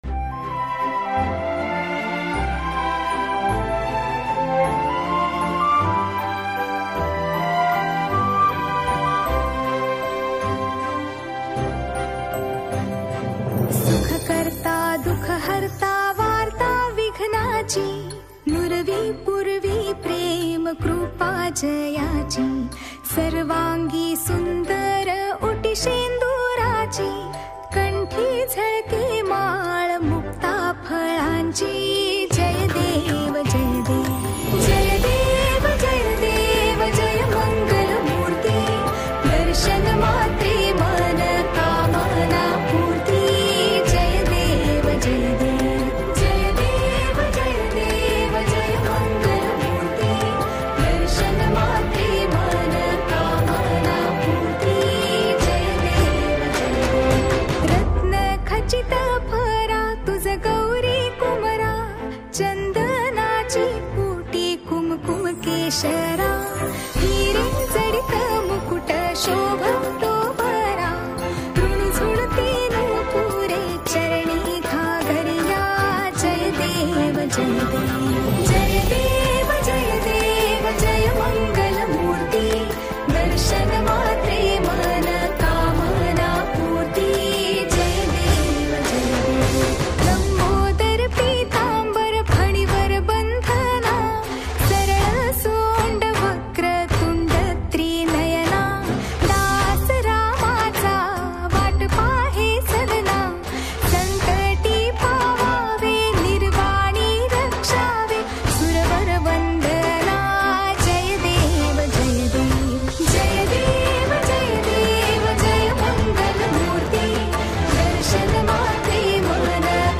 Ganesha Aarti